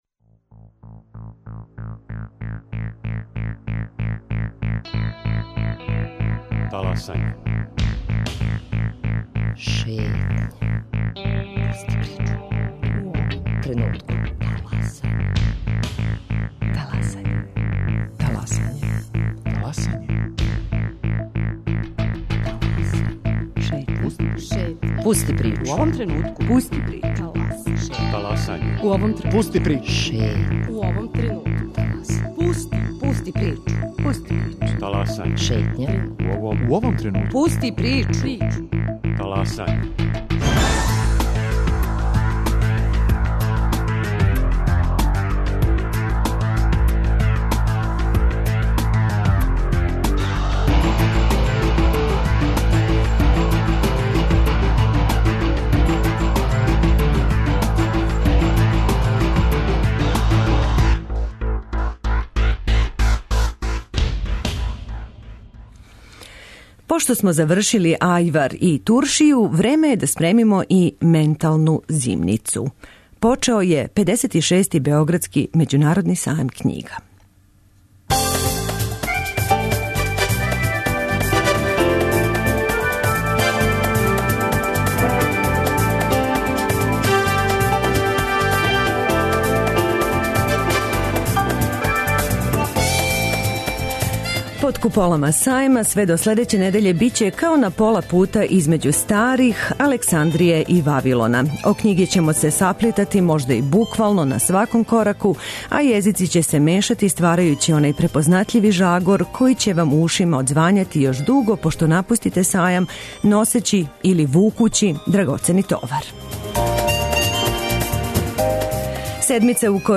Ове недеље шетамо 56. Сајмом књига у Београду – наш студио биће на сајму, разговараћемо са издавачима, писцима, посетиоцима... Почасни гост ове године су земље португалског говорног подручја - Ангола, Бразил, Гвинеја Бисао, Зеленортска острва, Источни Тимор, Мозамбик, Португал и Сао Томе.